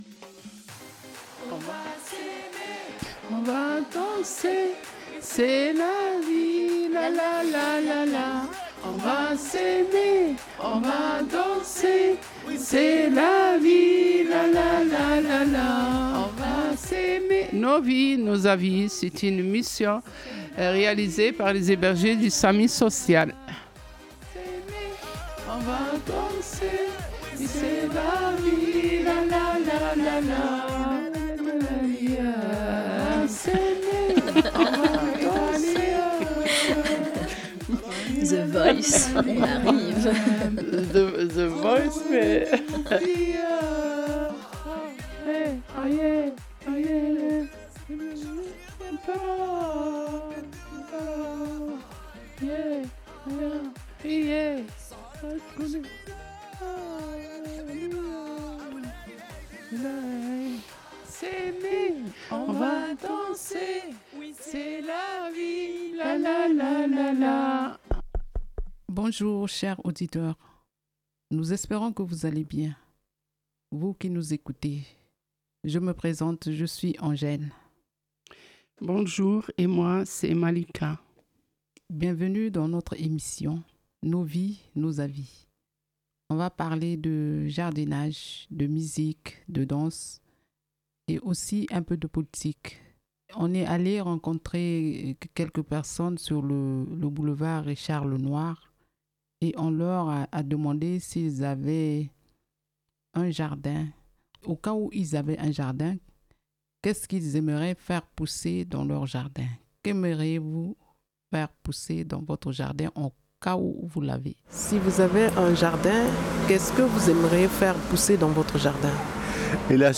Au micro, elles se racontent et échangent.